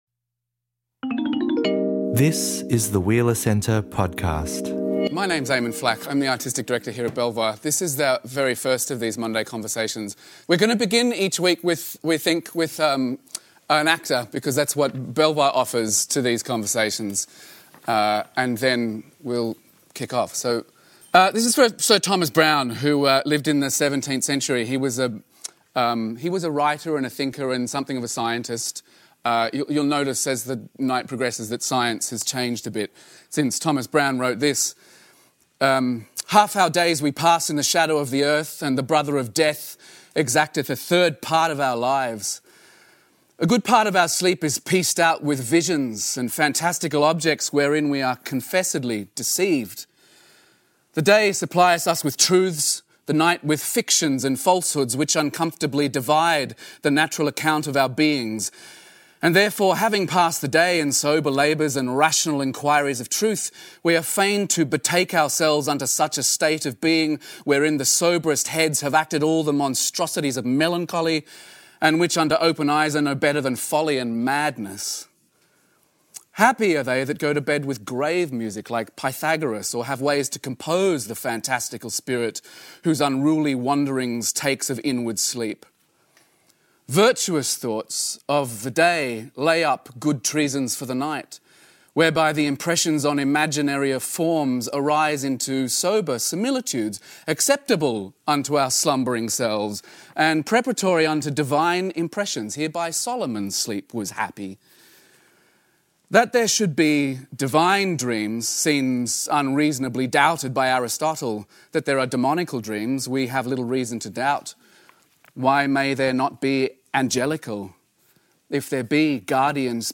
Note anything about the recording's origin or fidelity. Presented in partnership with Sydney Writers’ Festival and Belvoir.